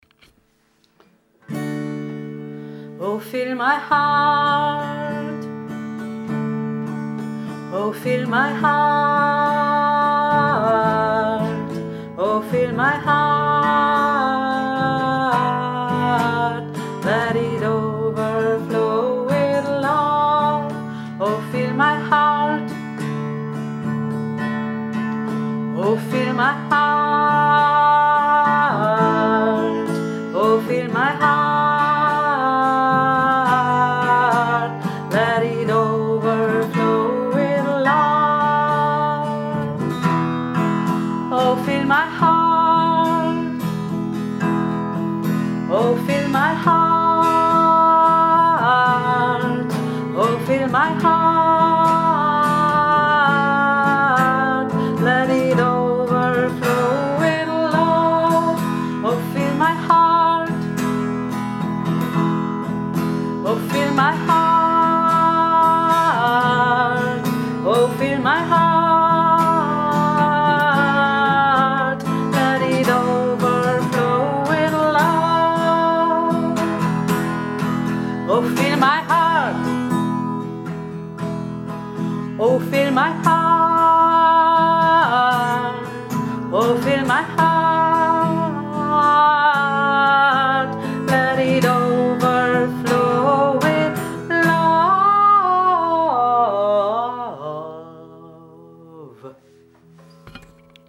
1. und 2. Stimme